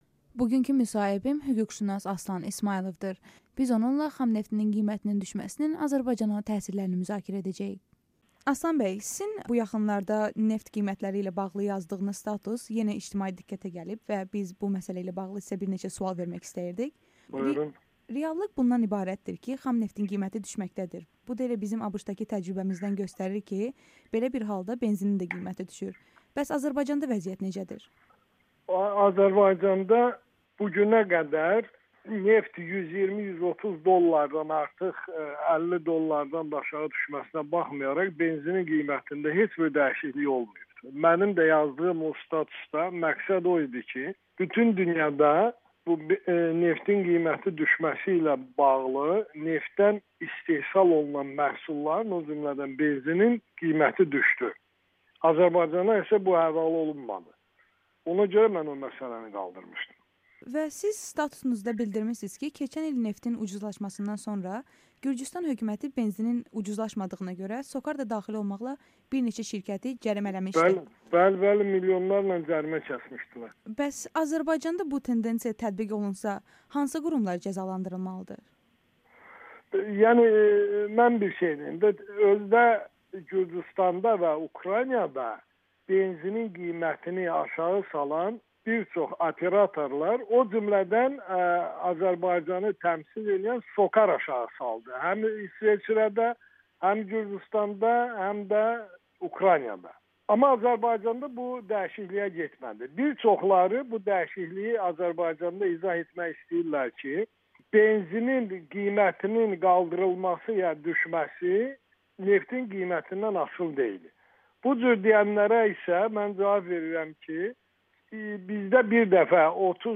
Hüquqşünasın Amerikanın Səsinə müsahibəsi